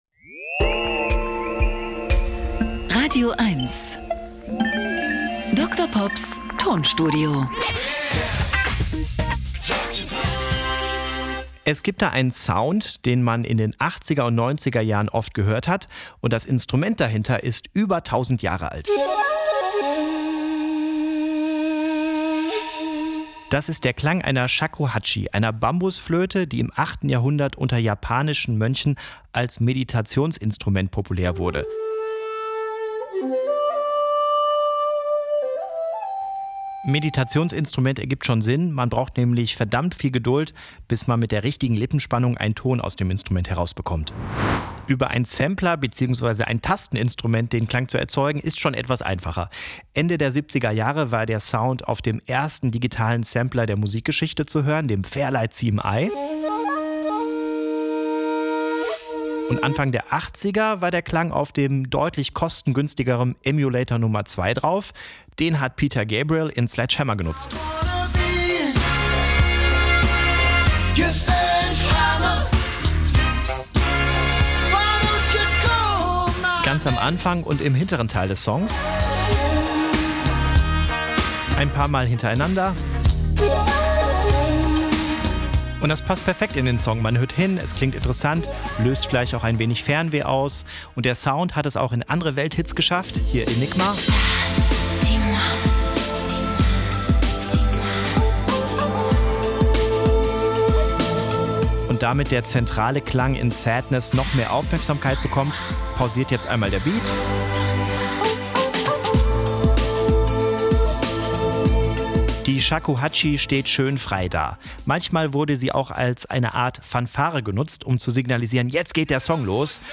Er therapiert mit Musiksamples und kuriosen, aber völlig wahren Musikfakten.
Comedy